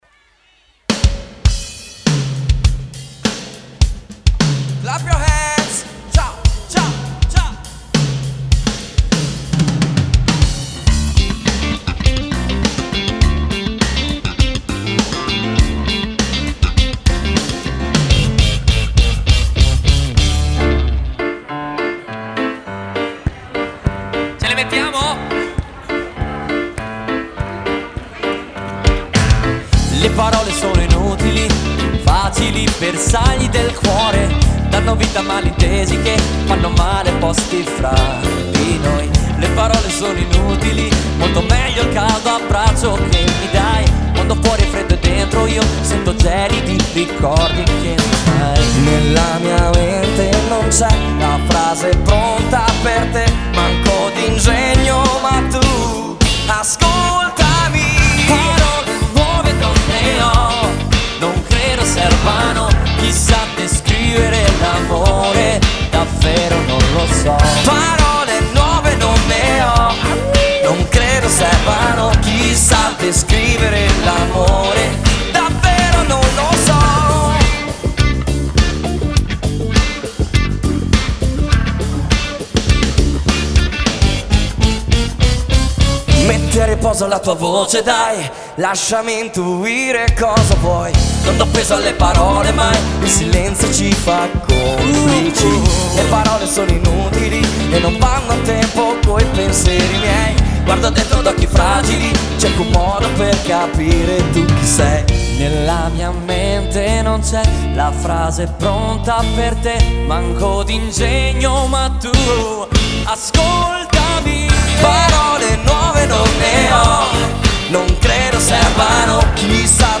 Pop (live)